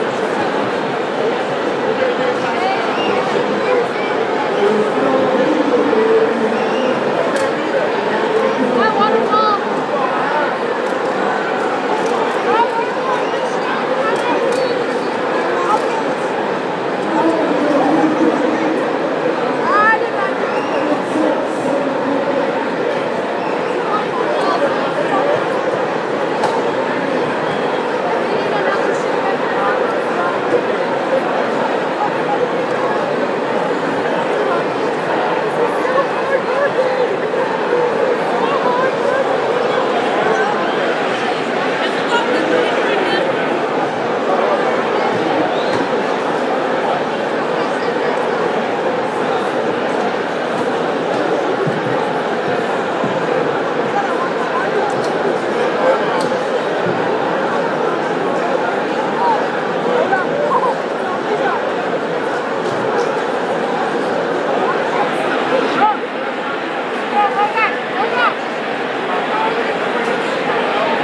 Boxing match between CPD and CFD at De La Salle Institute